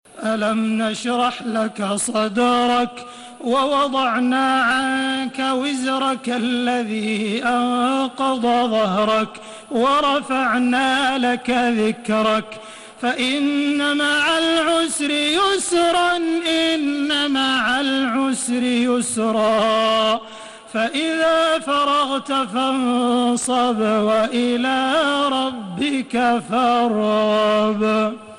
تراويح الحرم المكي 1432
مرتل